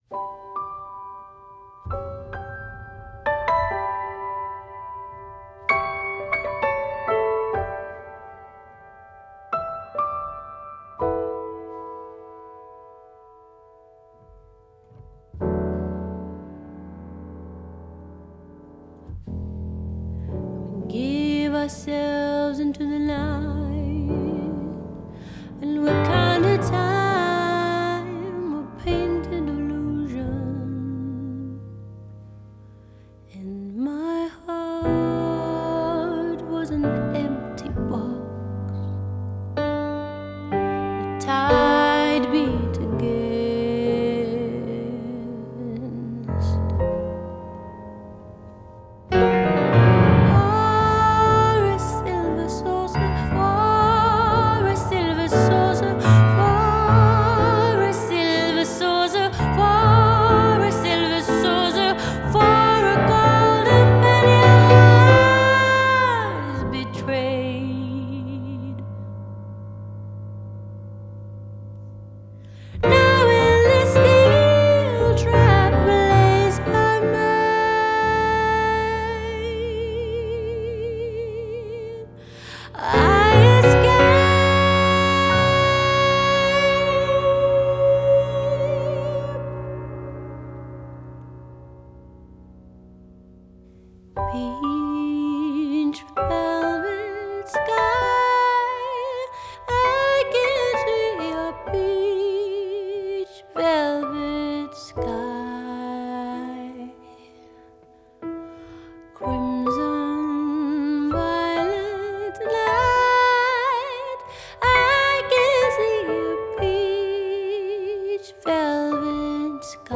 composition vocale suprême
l’accompagnement au piano